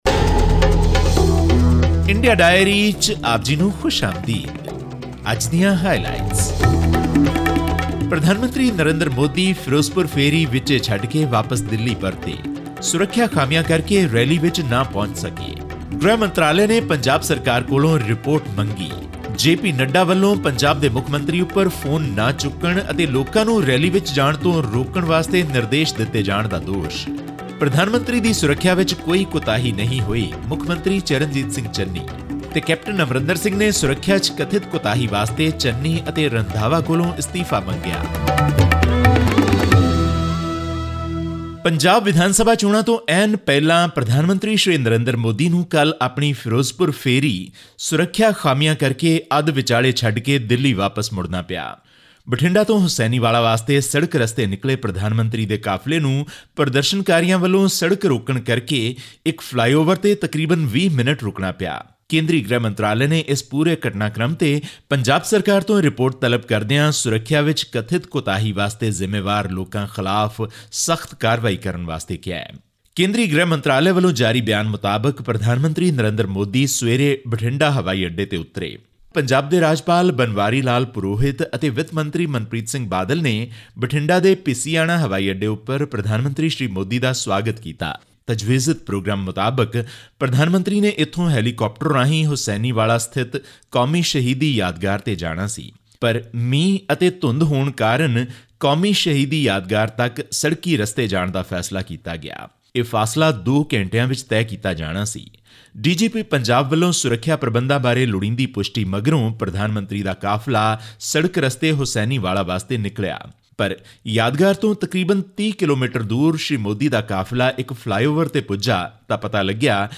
In response to criticism regarding PM Narendra Modi's security breach, Punjab Chief Minister Charanjit Singh Channi expressed regret for the 'unfortunate' incident, but denied the Centre's claims of a security lapse during Prime Minister's Ferozepur rally. All this and more in our weekly news segment from India.